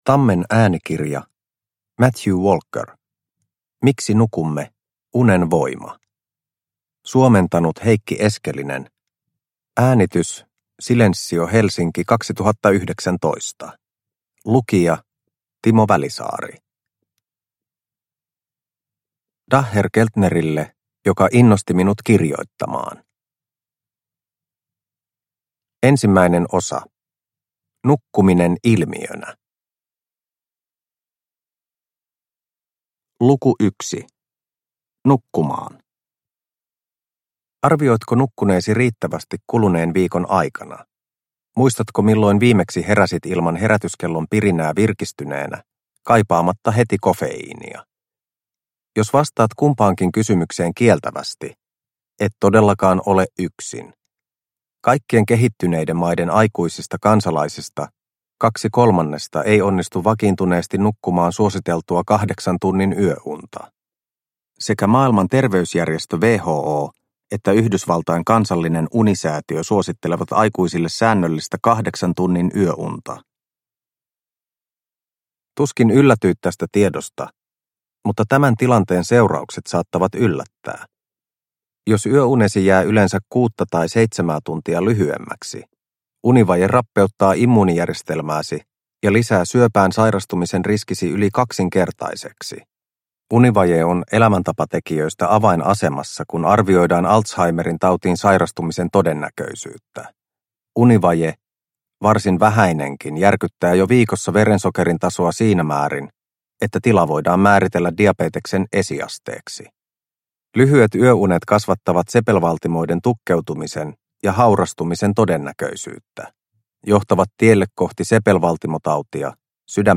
Miksi nukumme - Unen voima – Ljudbok – Laddas ner